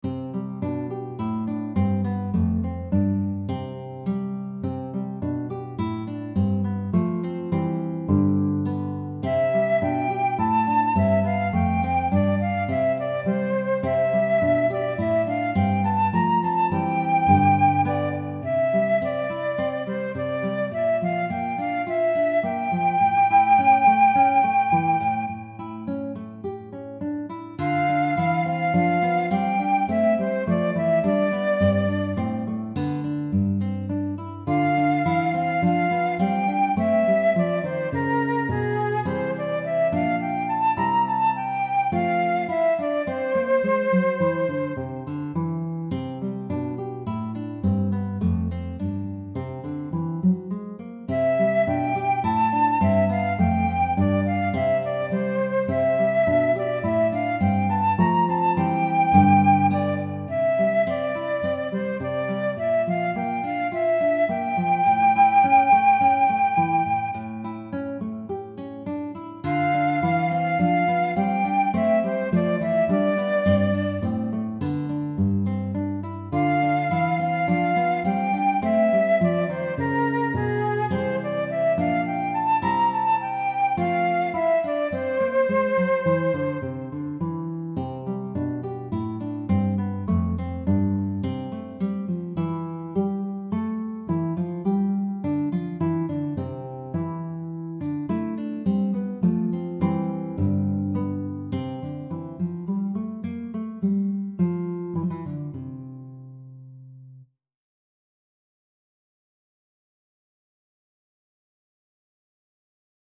The mp3 here is an electronic preview https